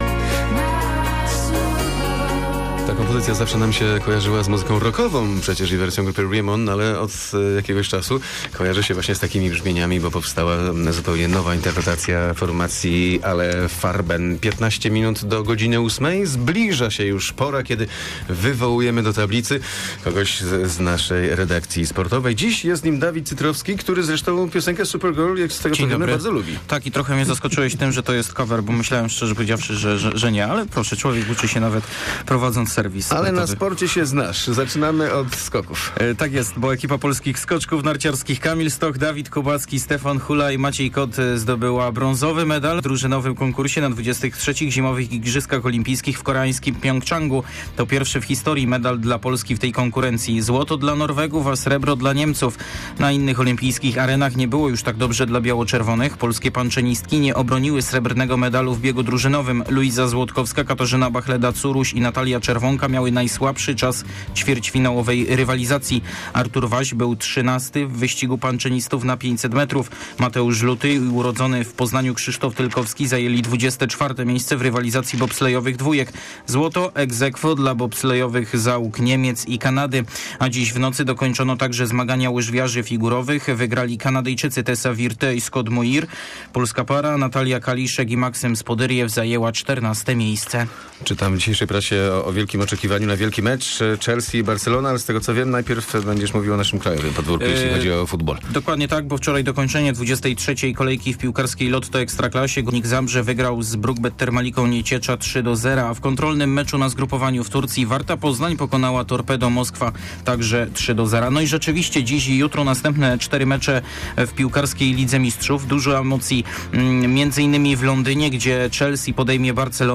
20.02 serwis sportowy godz. 7:45